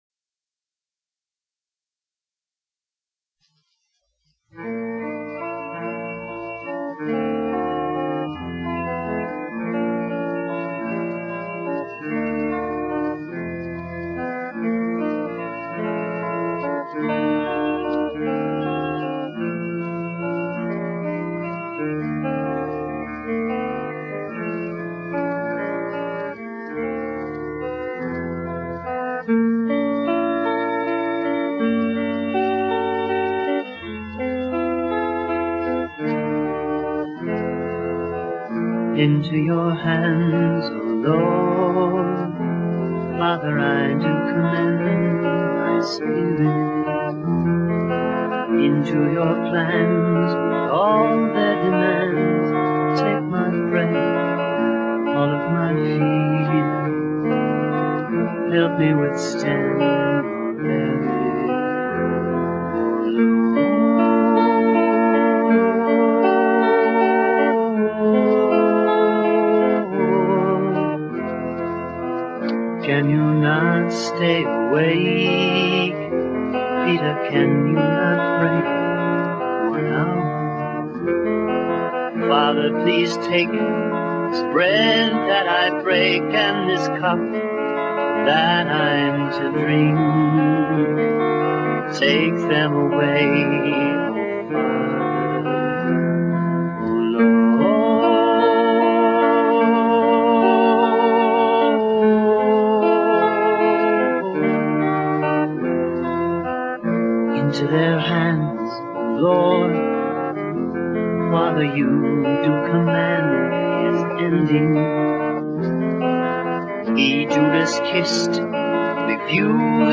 VOCALISTS
ORGAN, SYNTHESIZER
GUITAR, SYNTHESIZER
BASS GUITAR
DRUMS